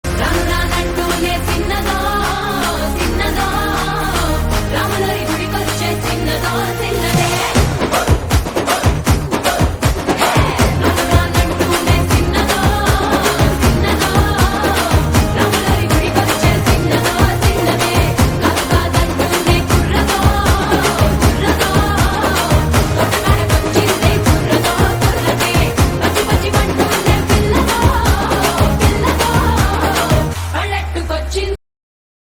A powerful, energetic and trending mobile ringtone .